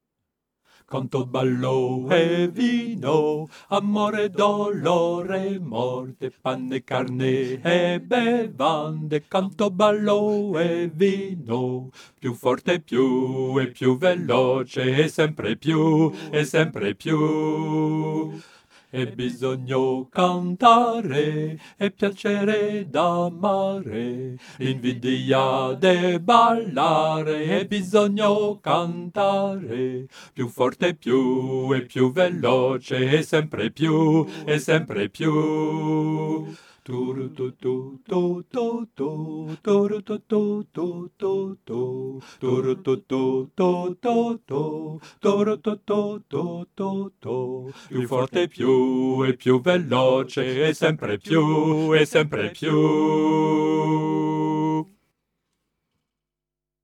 Basse
Cette chanson doit rester légère,